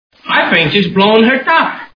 I Love Lucy TV Show Sound Bites